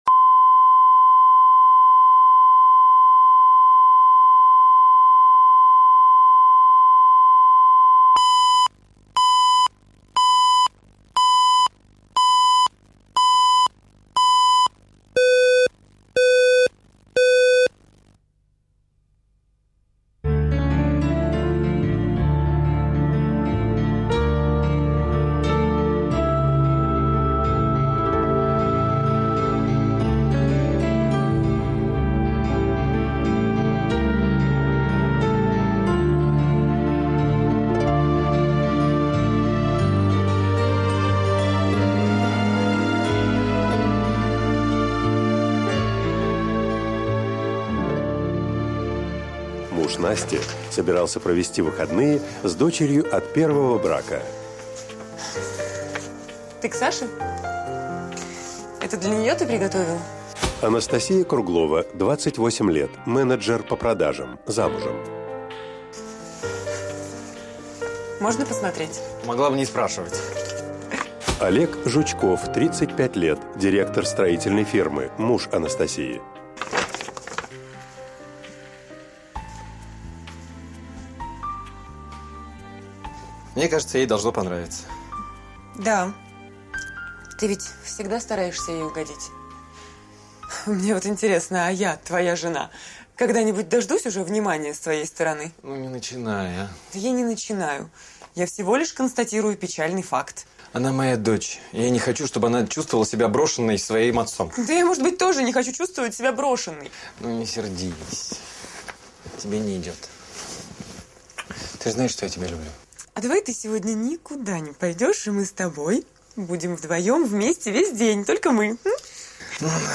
Aудиокнига Первый ребенок, вторая жена Автор Александр Левин.